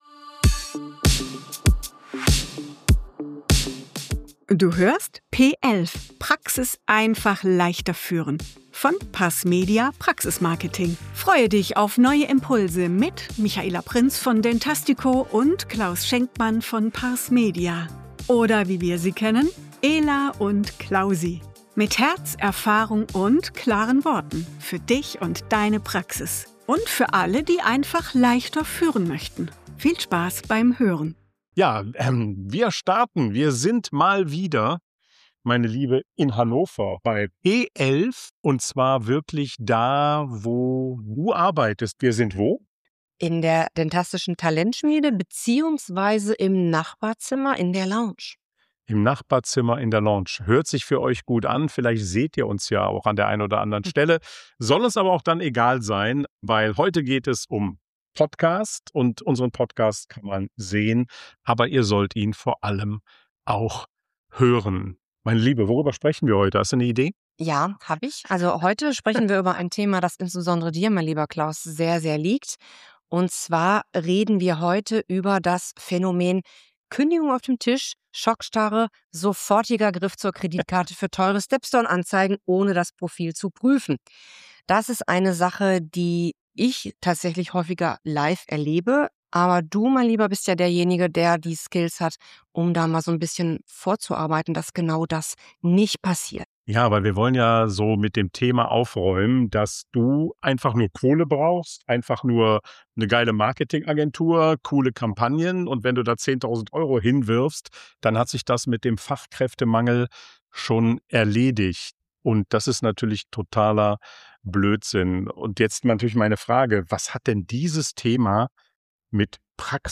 in der Talentschmiede von dentastico in Hannover